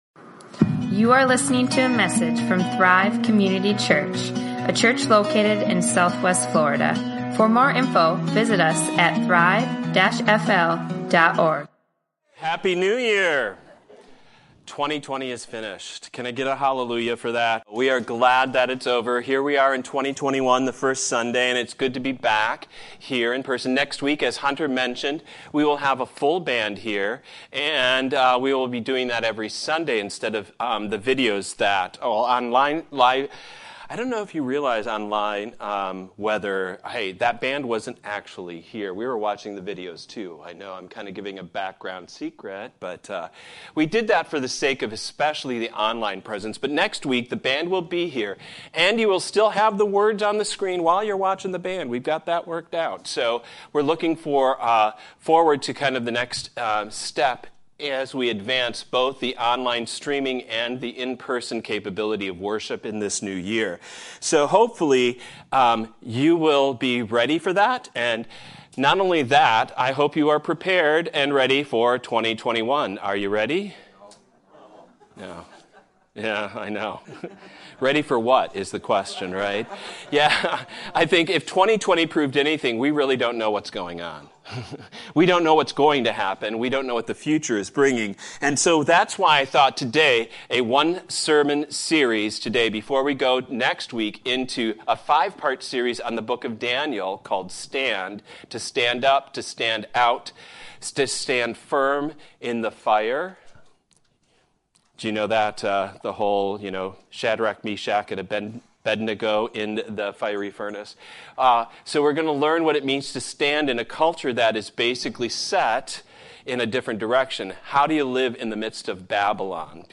Prepared | Sermons | Thrive Community Church